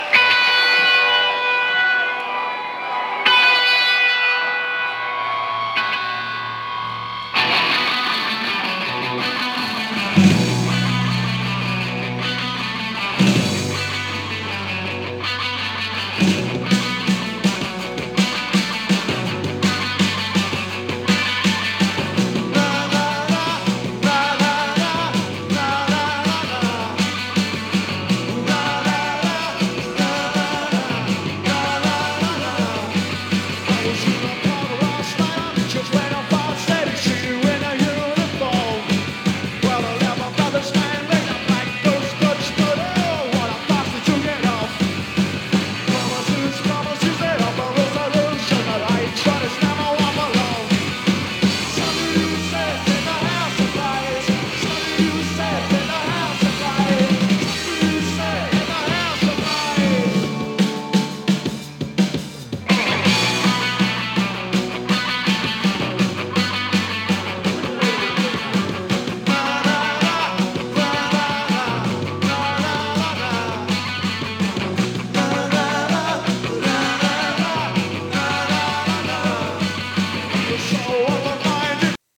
Live CD ネオモッズ
シールド新品 (試聴はLPからの流用)。